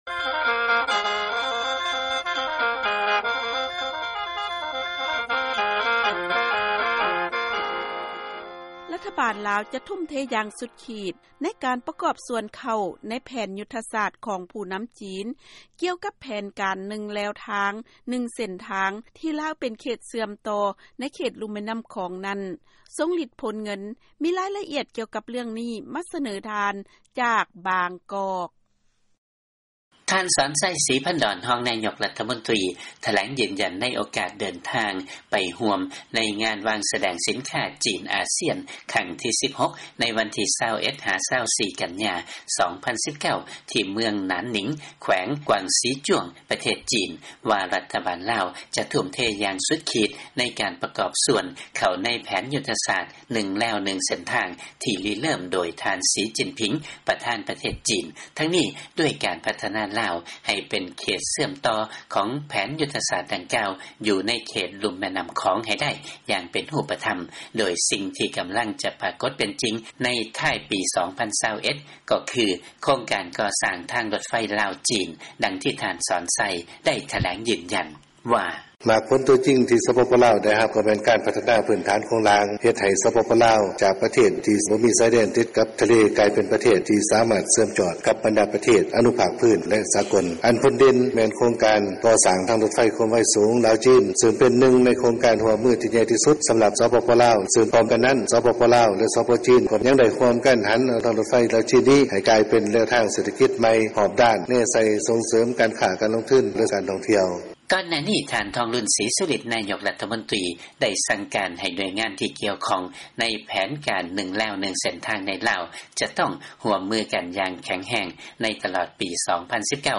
ທ່ານ ຈາກບາງກອກ.